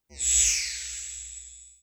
Turn Off Device.wav